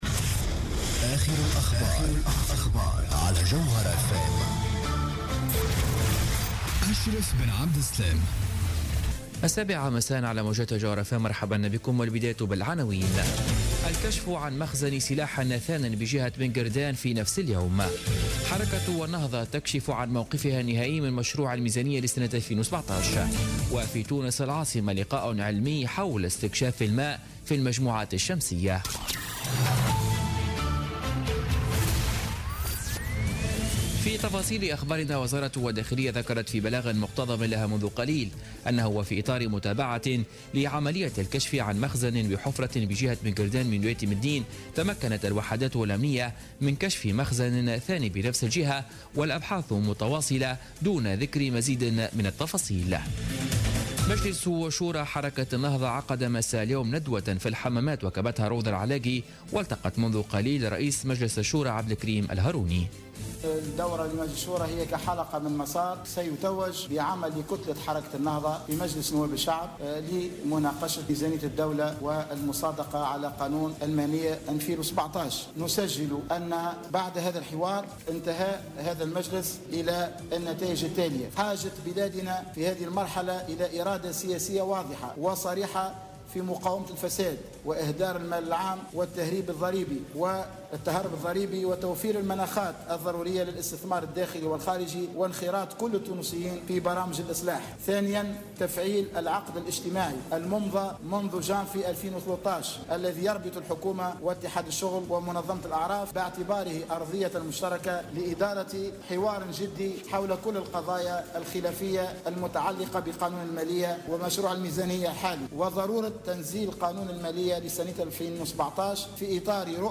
نشرة أخبار السابعة مساء ليوم السبت 12 نوفمبر 2016